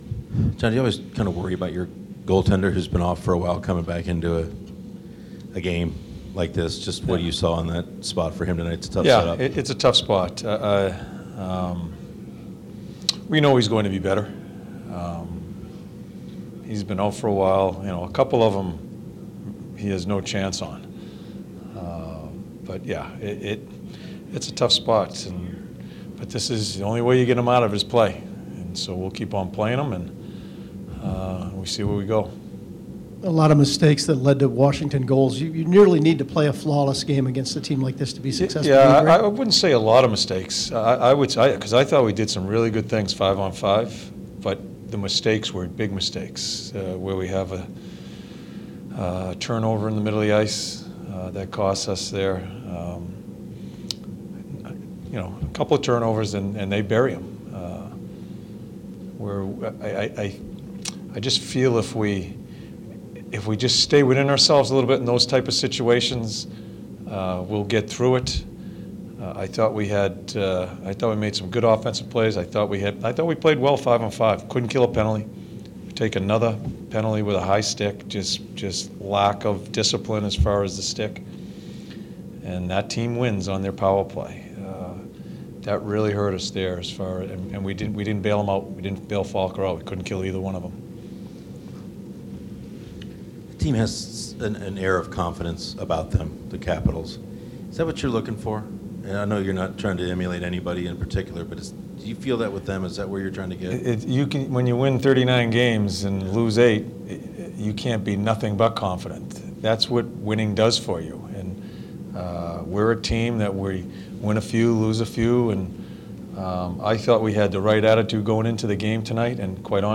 Tortorella's Post Game Interview after the Blue Jackets 6-3 loss to the Washington Capitals